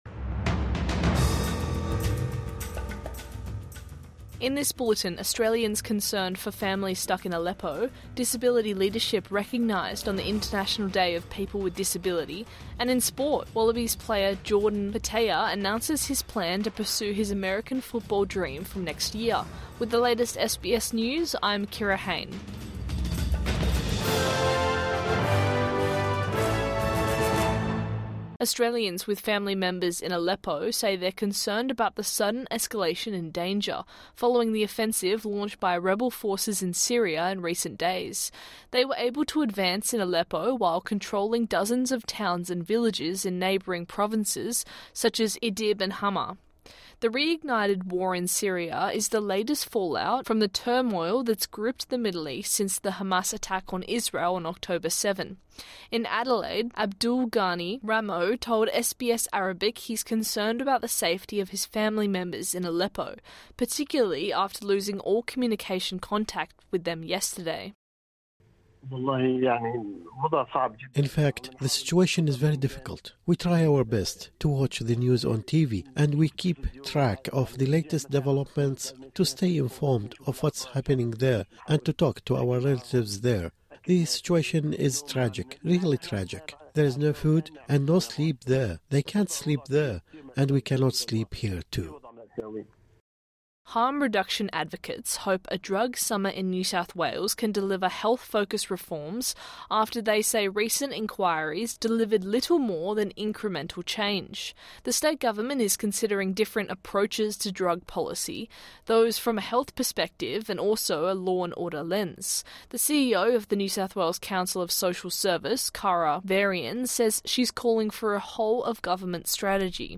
Midday News Bulletin 3 December 2024